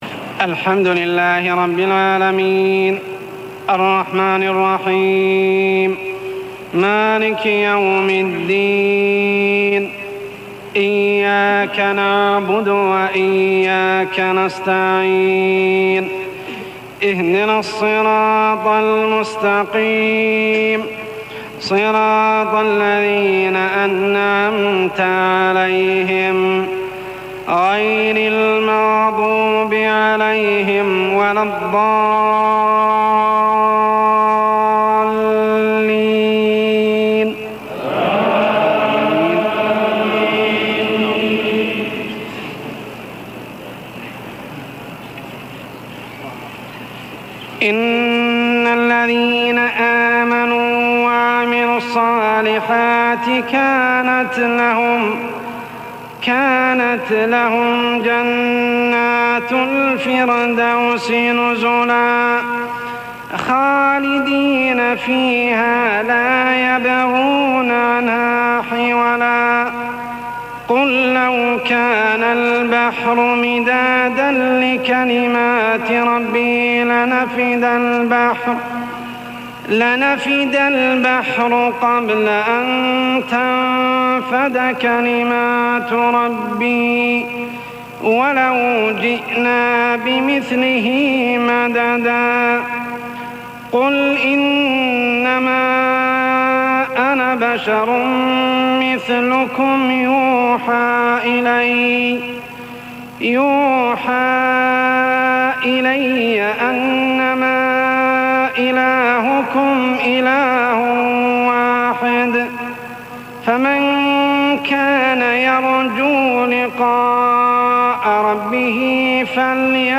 صلاة المغرب 6-9-1414هـ من سورتي الكهف 107-110 و مريم 96-98 | maghrib prayer surah Al-kahf and maryam > 1414 🕋 > الفروض - تلاوات الحرمين